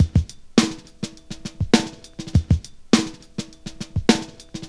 • 111 Bpm Drum Groove E Key.wav
Free breakbeat sample - kick tuned to the E note.
111-bpm-drum-groove-e-key-vpn.wav